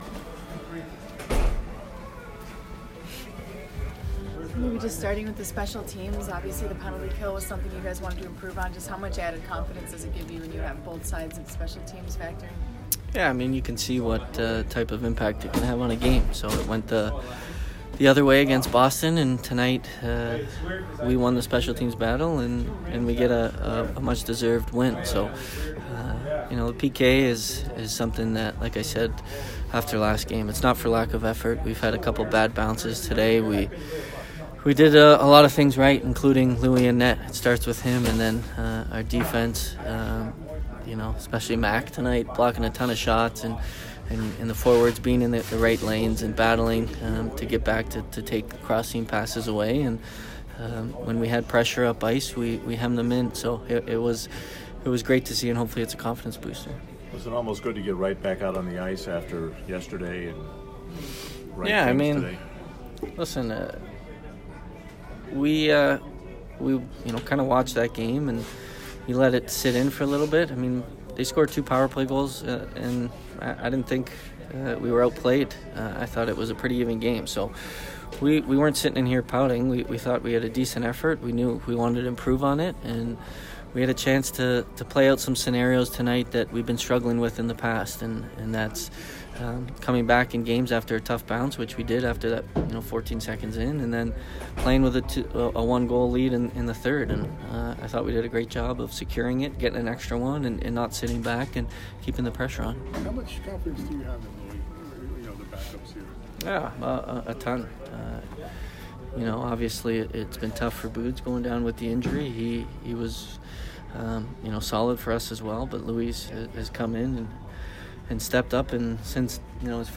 Steven Stamkos post-game 3/18